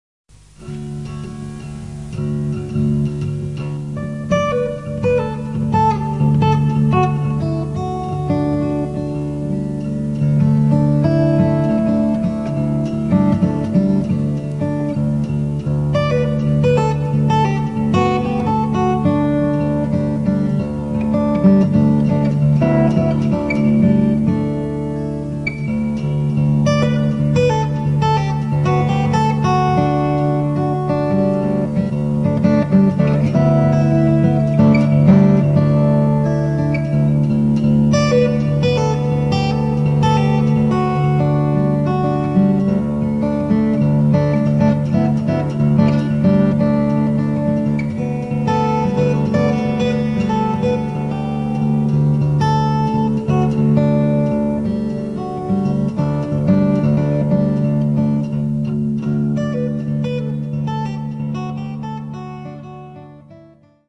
Basically, a warm, very gentle piece of guitar playing.